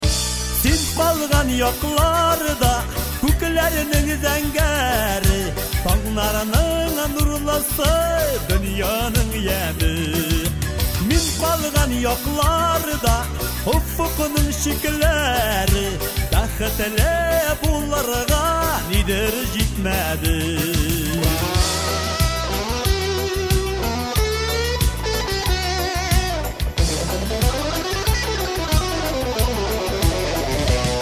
Kатегория: » Татарские рингтоны